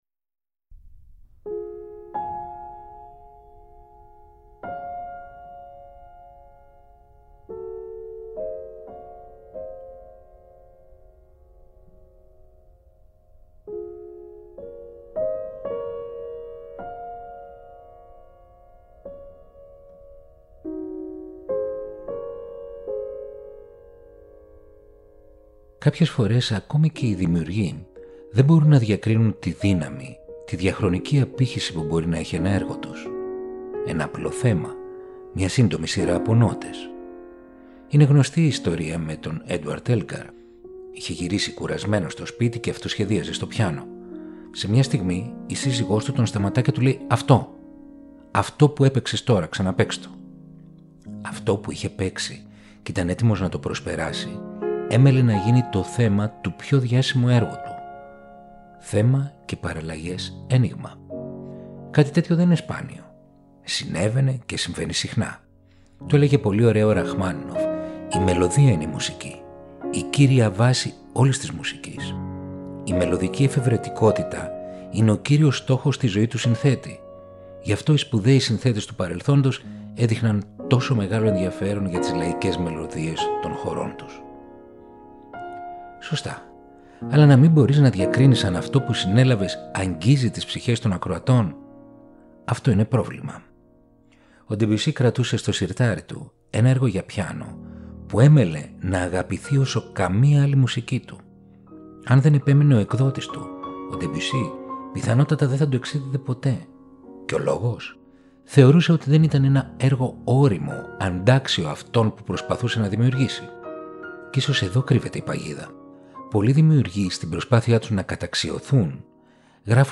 Η τζαζ συναντά την κλασσική μουσική